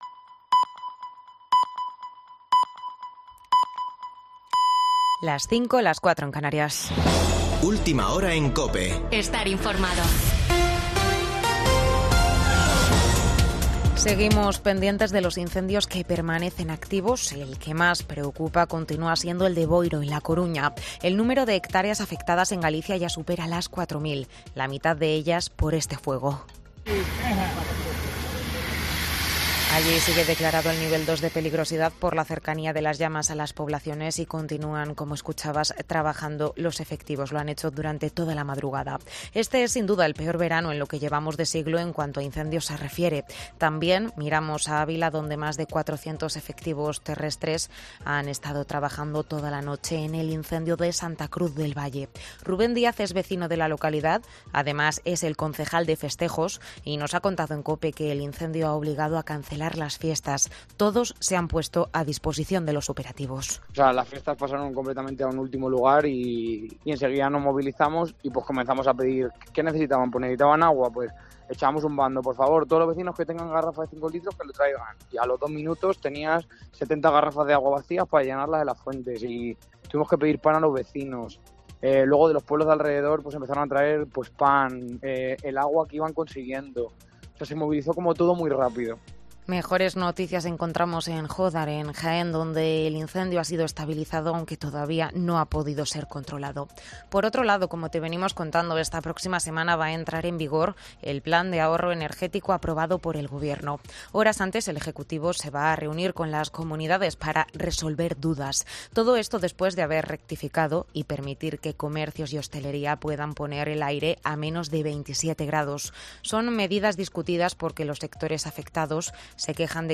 Boletín de noticias de COPE del 7 de agosto de 2022 a las 05.00 horas